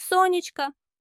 SOH-nech-ko little sun / sunshine